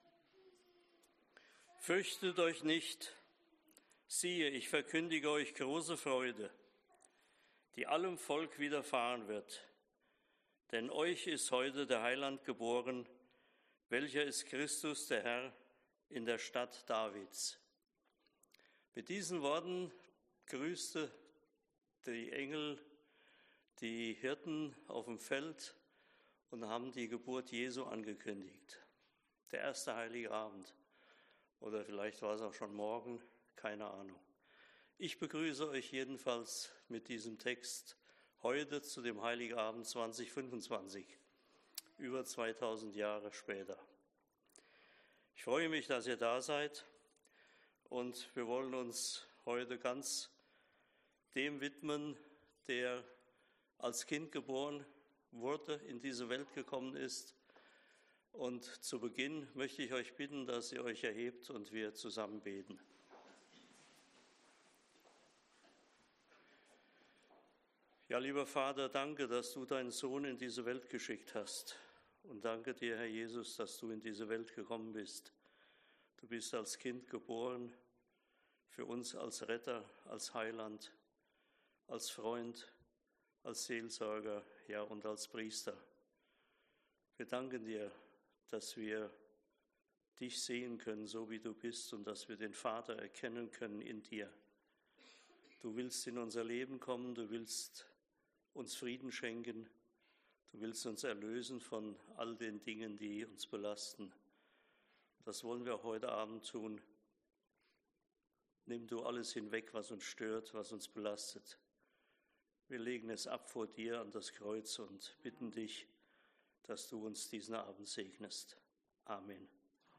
Heilig Abend C1 - Christus zuerst, eins in Christus